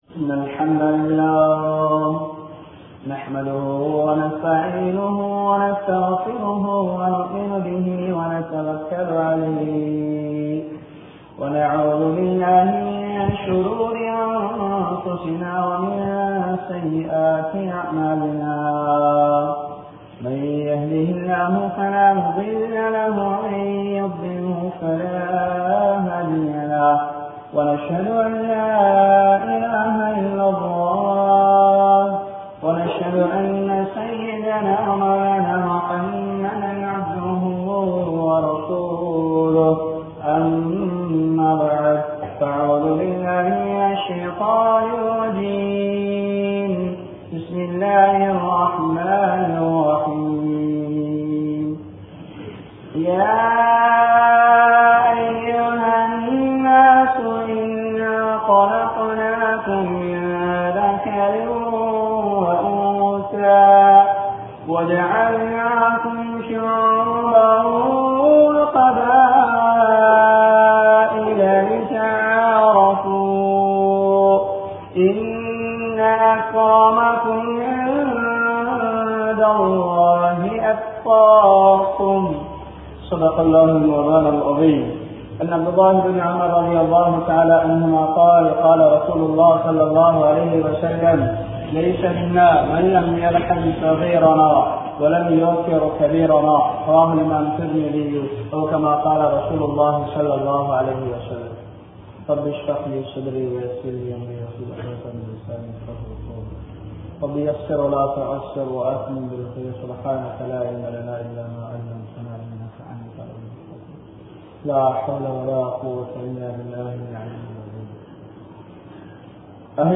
Muthiyorkalai Mathippom (முதியோர்களை மதிப்போம்) | Audio Bayans | All Ceylon Muslim Youth Community | Addalaichenai